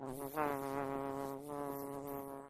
bee_buzz_short.ogg